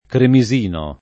vai all'elenco alfabetico delle voci ingrandisci il carattere 100% rimpicciolisci il carattere stampa invia tramite posta elettronica codividi su Facebook cremisino [ kremi @& no ] o chermisino [ kermi @& no ] agg. e s. m. — sim. il cogn.